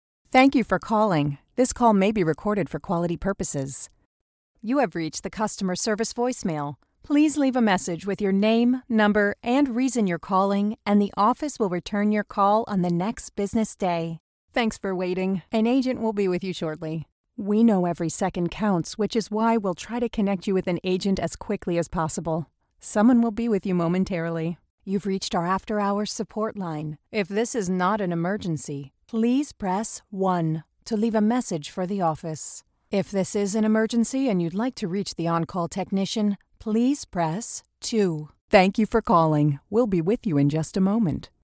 Sou especialista em leituras comerciais autênticas e coloquiais com um tom caloroso e jovem. Fui descrita como tendo uma qualidade vocal amigável e relacionável.